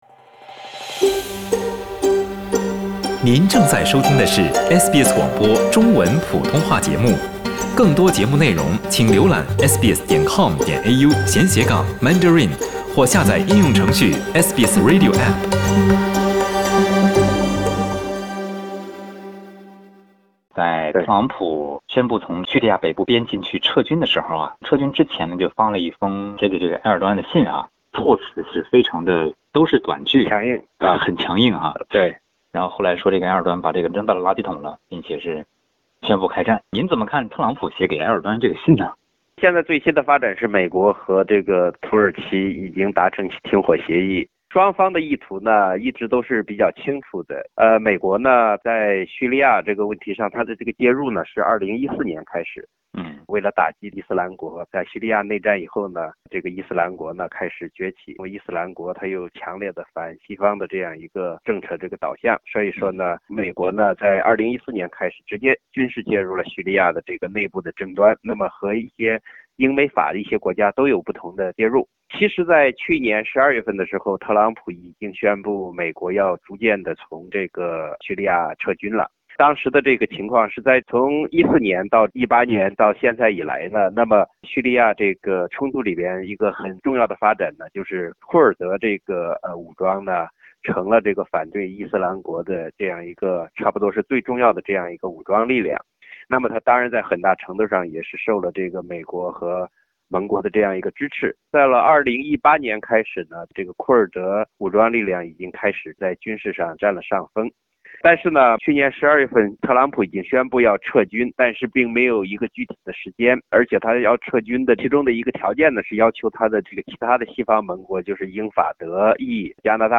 请您点击收听详细的采访内容。